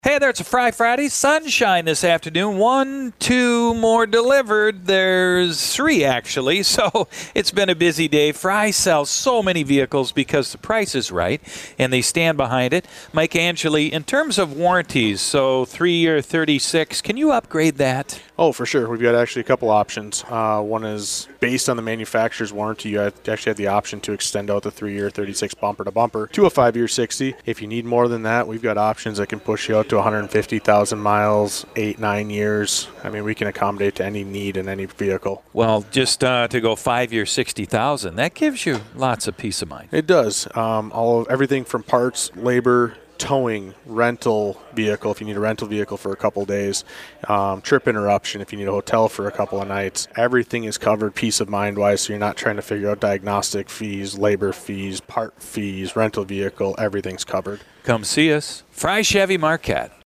We're live all afternoon at Frei Chevrolet.